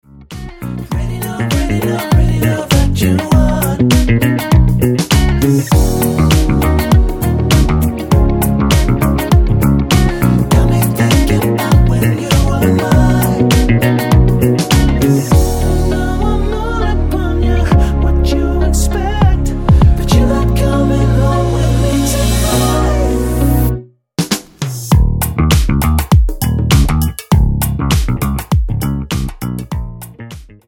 --> MP3 Demo abspielen...
Tonart:Ebm mit Chor